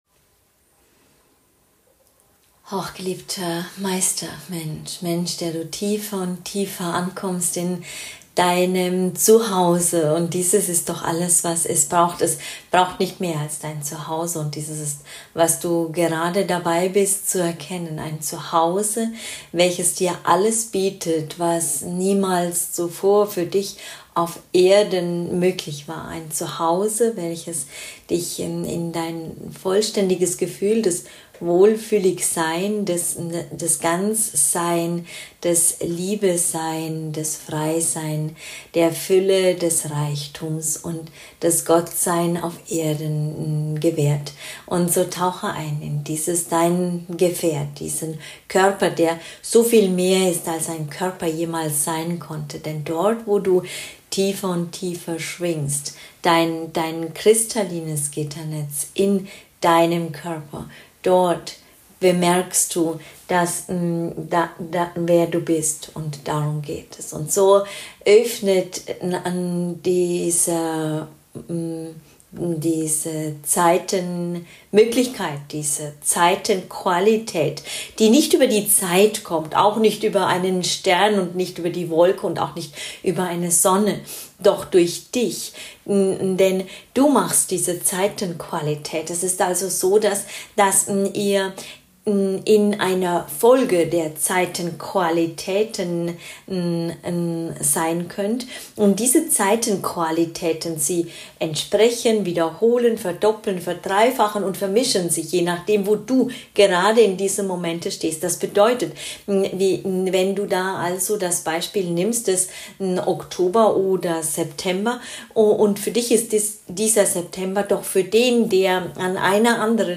Channeling | FrequenzBad in der aktuellen ZeitQualität ~ MenschSein - musst du leben. Nicht denken.